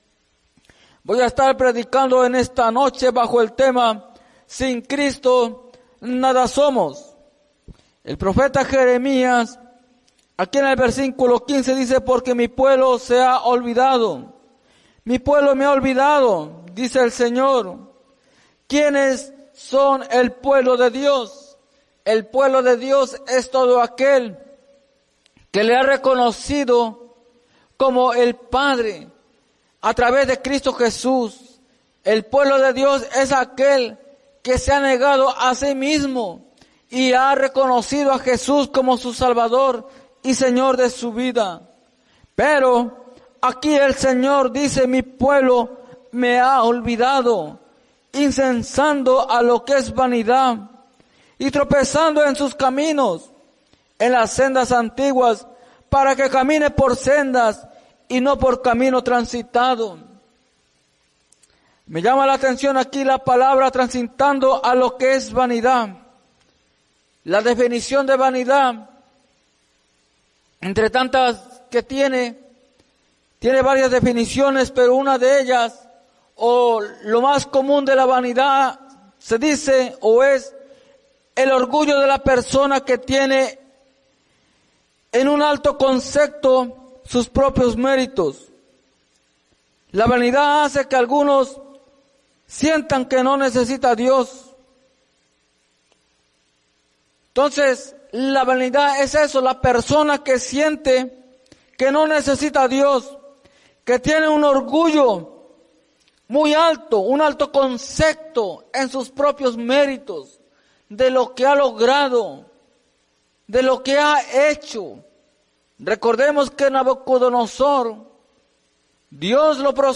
Mensaje
en la Iglesia Misión Evangélica en Norristown, PA